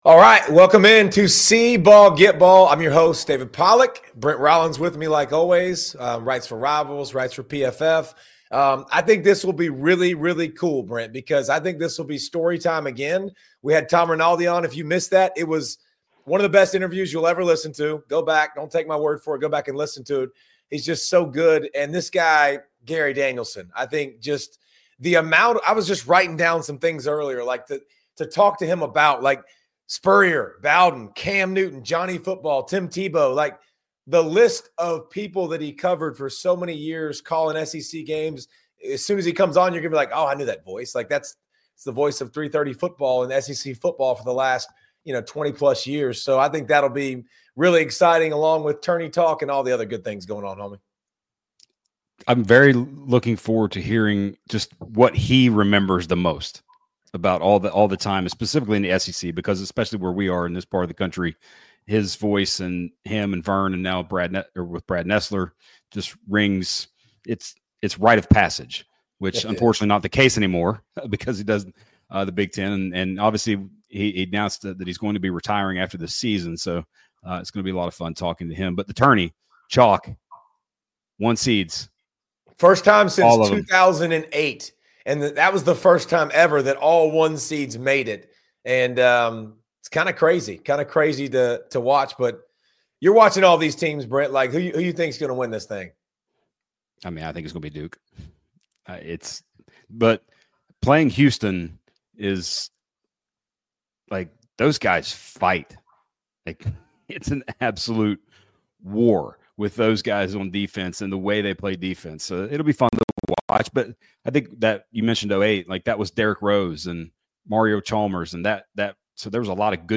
Hosted by David Pollack, a College Football Hall of Famer, three-time First-Team All-American from the University of Georgia, and first-round NFL Draft pick, this podcast provides honest analysis of the most significant stories, matchups, and moments in the world of college football. David shares his passion for college football and extensive knowledge through conversations with top coaches, including Marcus Freeman, Kirby Smart, Brett Bielema, and Dan Mullen, as well as current players, legends, and insiders from various conferences.